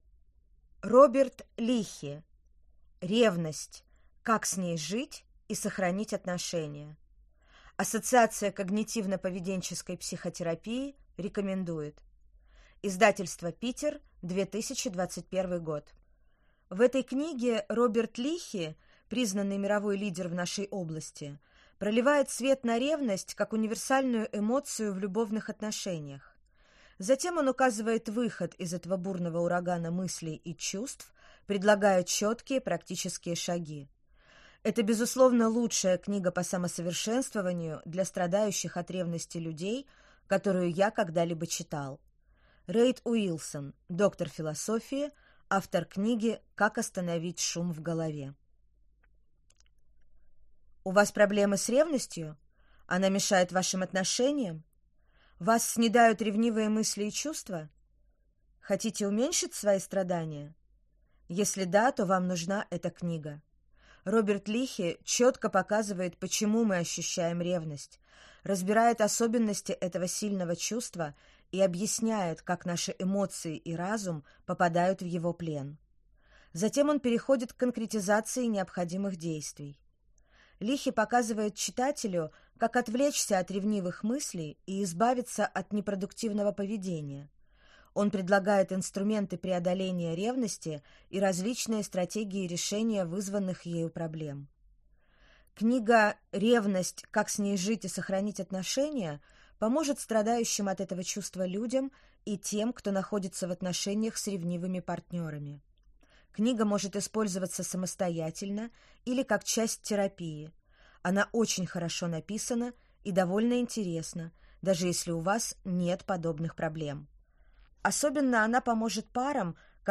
Аудиокнига Ревность. Как с ней жить и сохранить отношения | Библиотека аудиокниг